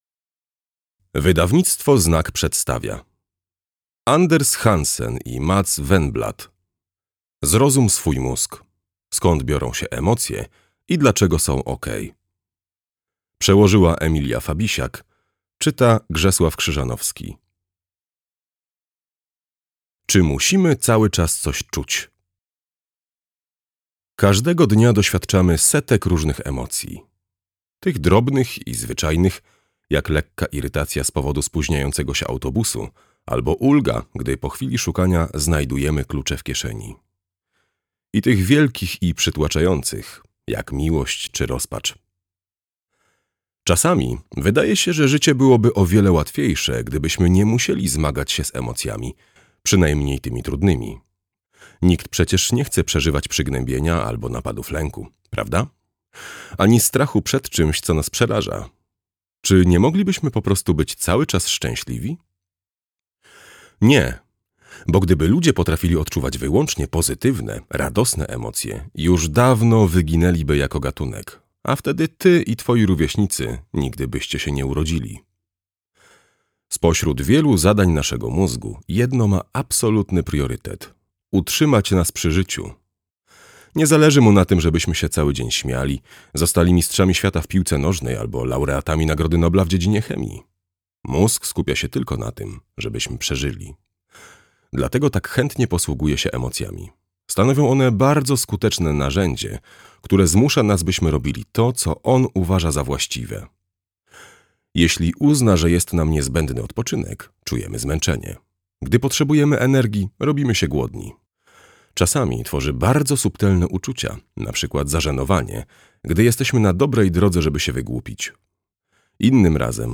Skąd biorą się emocje i dlaczego są OK - Andres Hansen, Mats Wänblad - audiobook